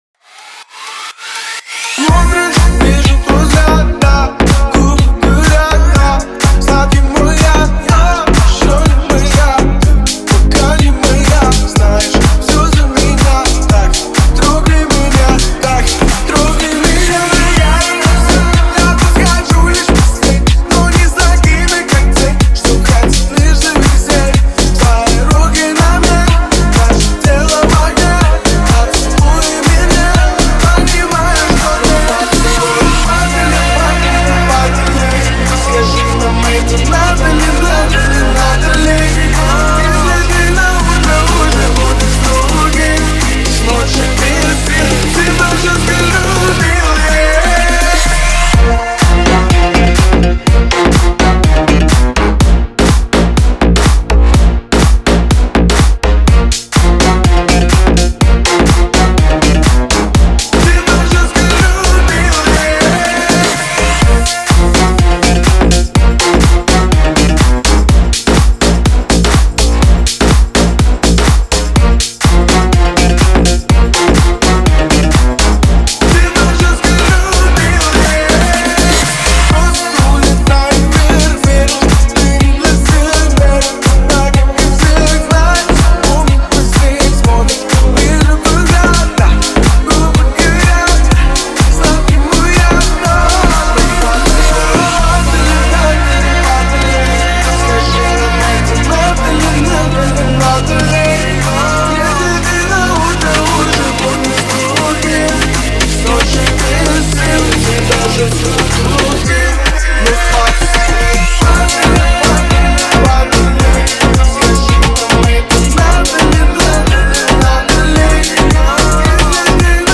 мелодичный вокал